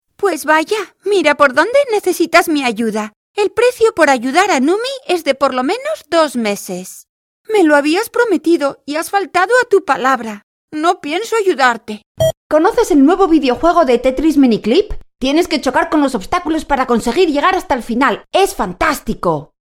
Espagnol
Échantillons de voix natifs
Démo commerciale